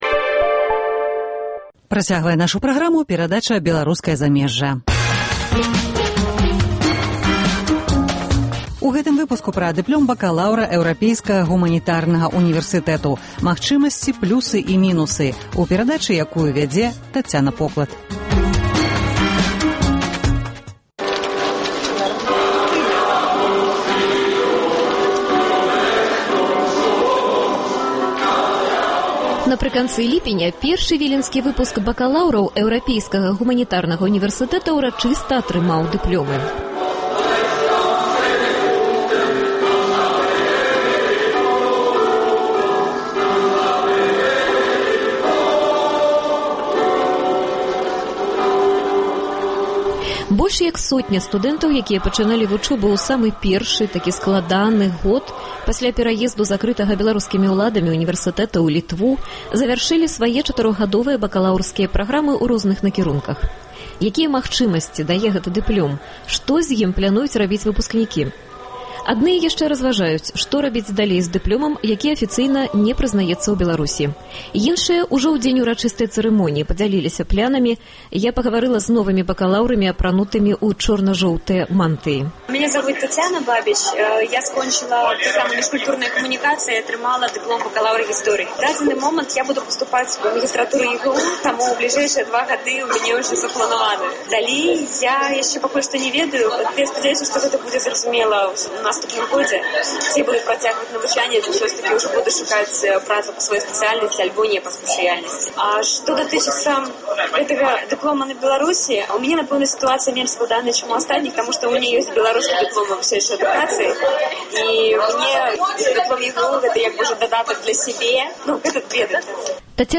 Першы выпуск бакаляўраў, якія пачыналі вучобу у Вільні, атрымаў дыплёмы Эўрапейскага гуманітарнага унівэрсытэту, што цягам апошніх чатырох гадоў працуе у Літве як беларускі унівэрсытэт у выгнаньні. Дыплём бакаляўра ЭГУ – пра яго магчымасьці, плюсы і мінусы – у новай перадачы Беларускае замежжа з удзелам выпускнікоў, выкладчыкаў і новых студэнтаў, прыём якіх адбываецца акурат цяпер